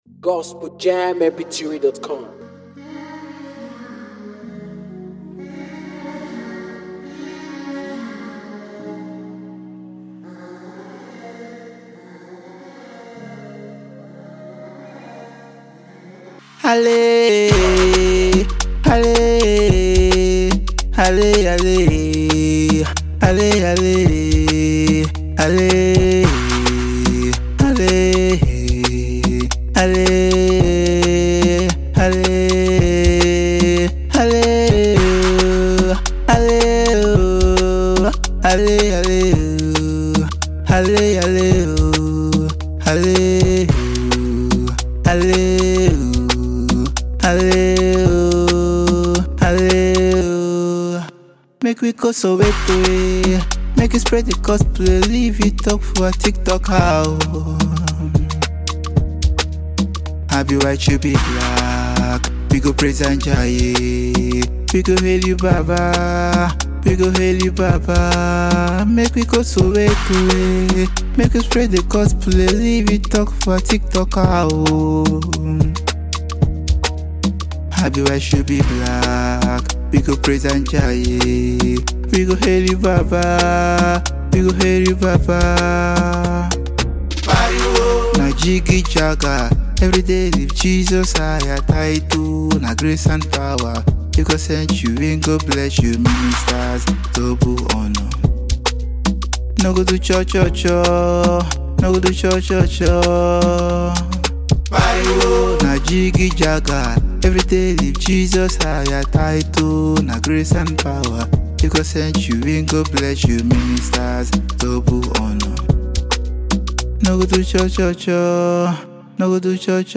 Afro Gospel Song